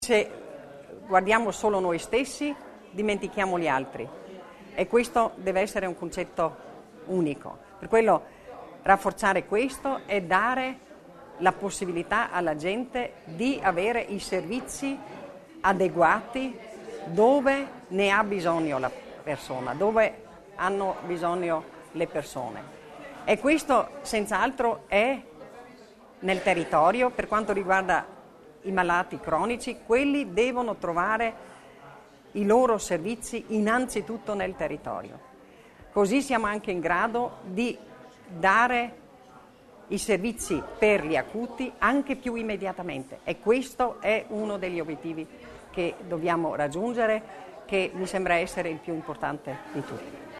L'Assessore Stocker illustra le priorità in ambito sanitario
Rafforzare la responsabilizzazione personale, Non lasciare indietro nessuno, Rendere più semplice la vita ai cittadini: i temi della conf. stampa di oggi.